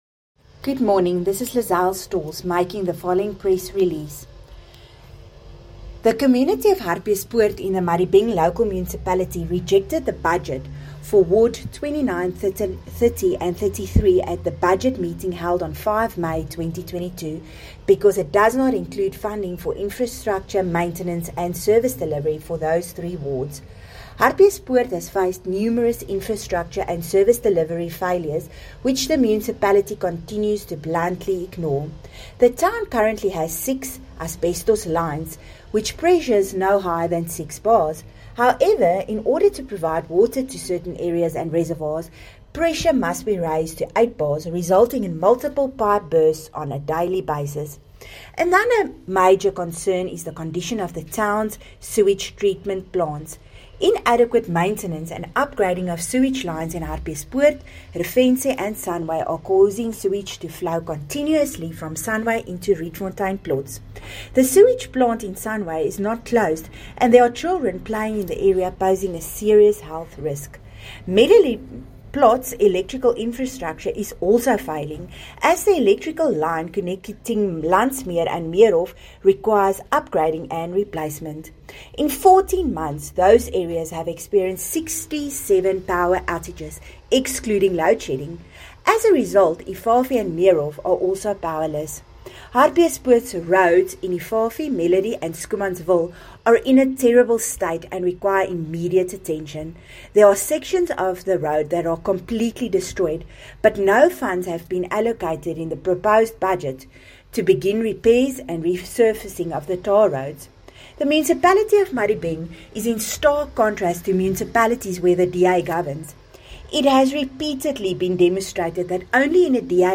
Note to Editors: Please find attached soundbites in
Cllr-Lizelle-Stoltz-Madibeng-Budget-Eng.mp3